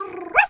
dog_2_small_bark_05.wav